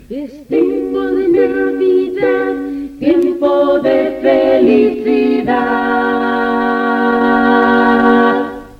Indicatiu del Nadal 1982